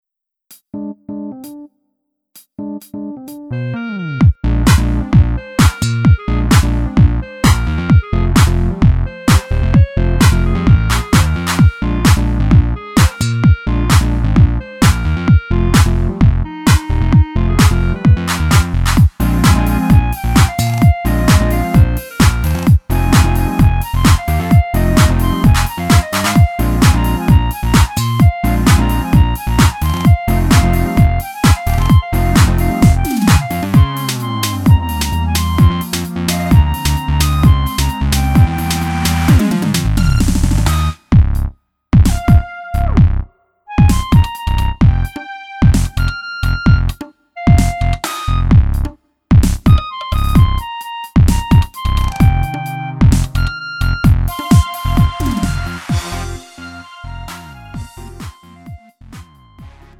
음정 원키 3:13
장르 구분 Lite MR